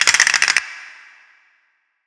DDW4 SALSA.wav